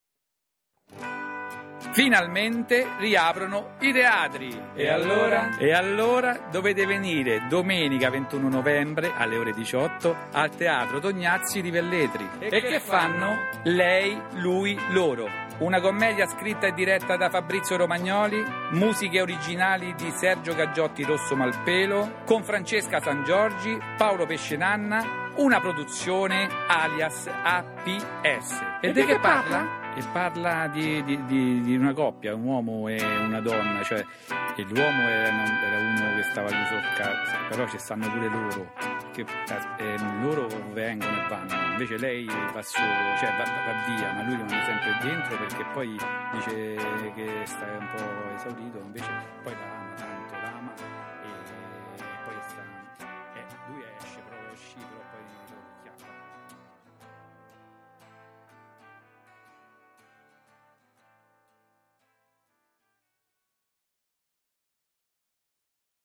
Jingle spettacolo Assolutamente da ascoltare!!!